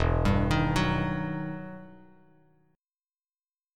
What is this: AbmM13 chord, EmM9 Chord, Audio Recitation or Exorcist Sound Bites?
EmM9 Chord